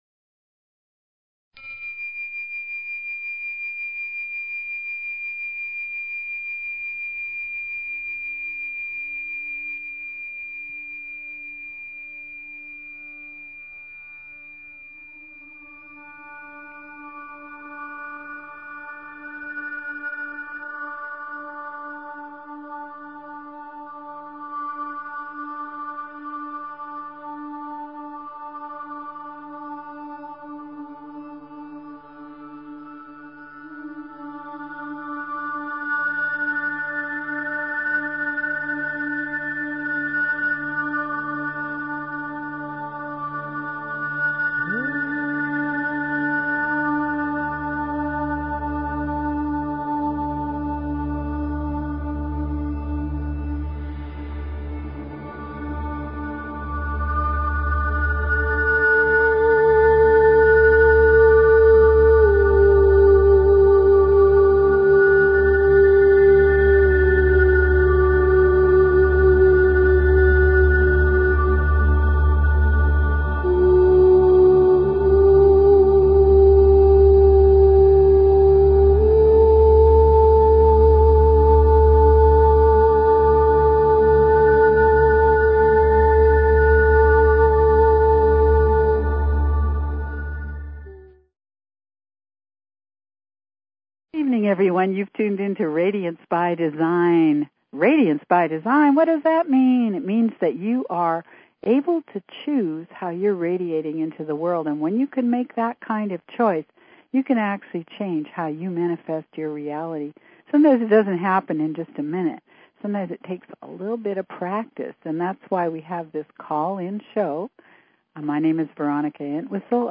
Talk Show Episode, Audio Podcast, Radiance_by_Design and Courtesy of BBS Radio on , show guests , about , categorized as
This is a call in show so call in!